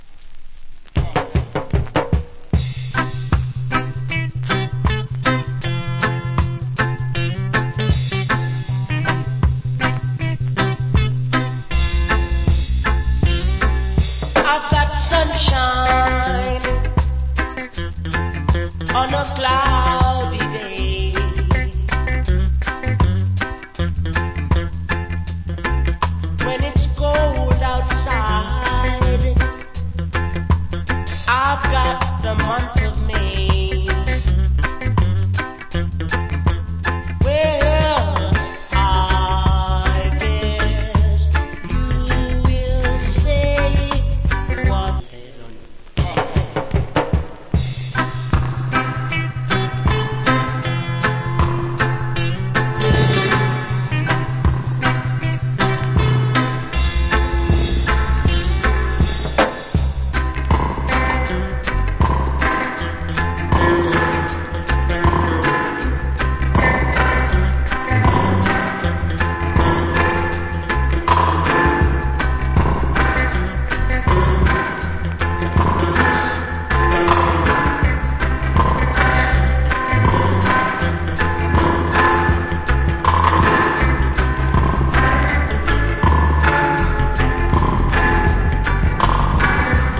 Nice Reggae Vocal. Cover Song.